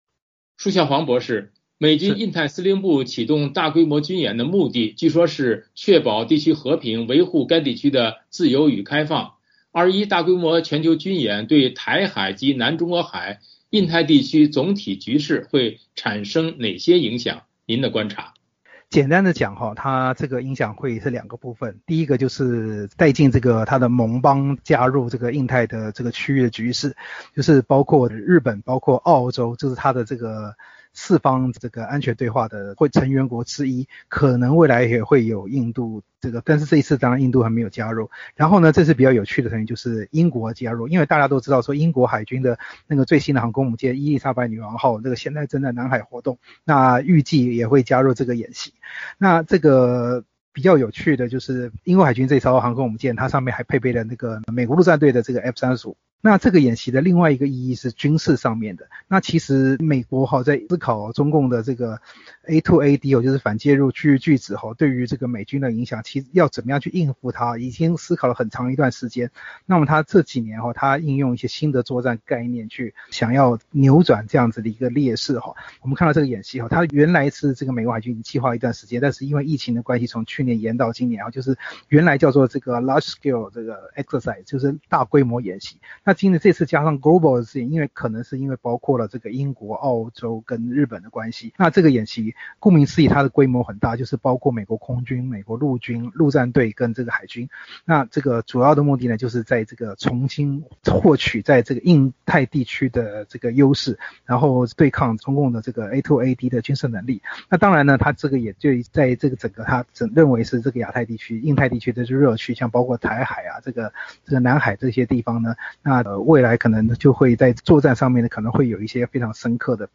新闻及采访音频